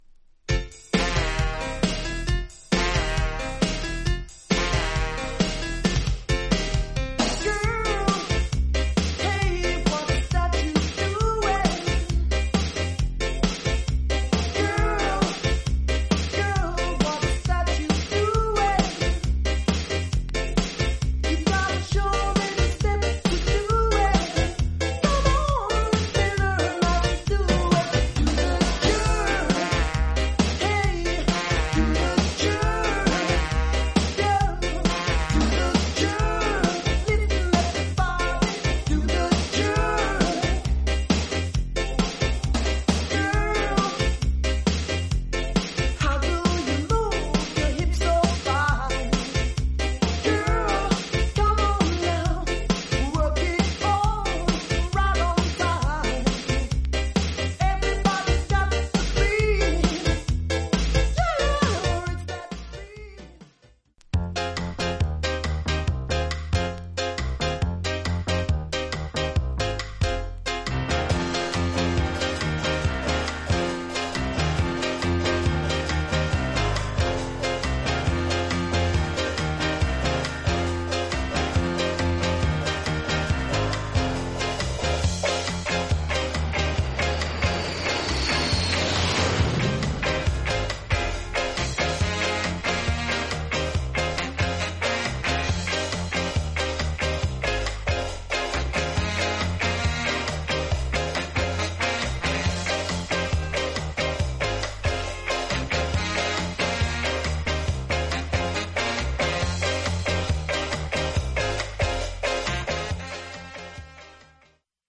類別 SKA
実際のレコードからのサンプル↓